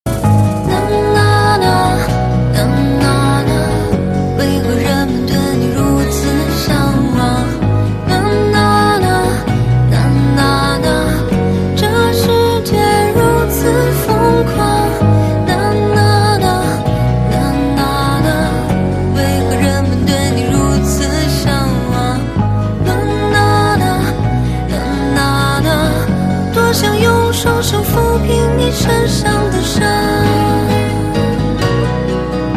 M4R铃声, MP3铃声, 华语歌曲 51 首发日期：2018-05-14 09:07 星期一